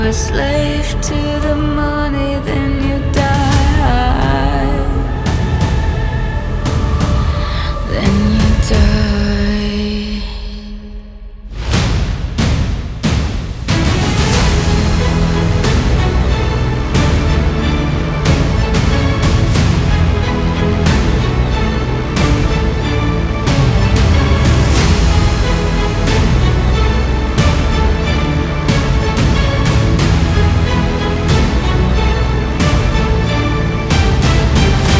# Рок